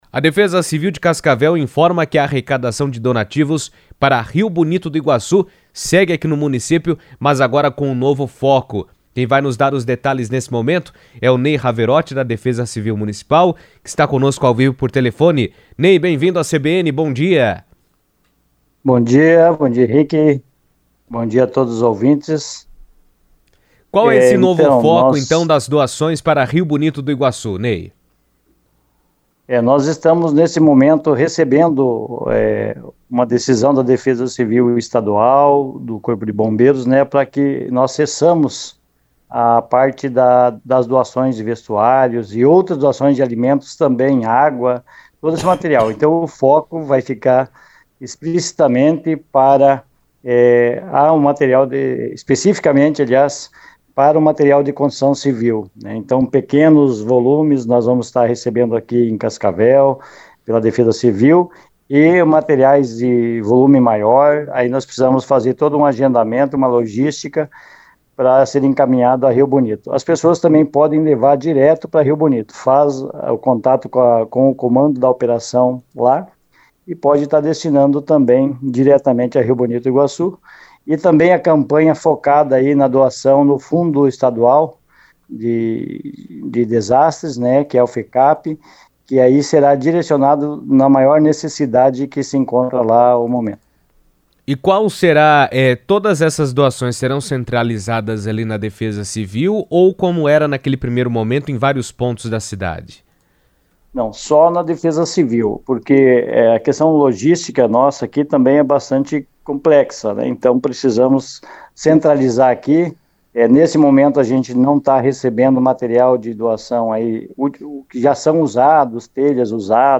A Defesa Civil de Cascavel reforçou que a campanha de apoio a Rio Bonito do Iguaçu agora está concentrada na arrecadação de materiais de construção, itens considerados essenciais para a recuperação das famílias afetadas. Durante participação ao vivo por telefone na CBN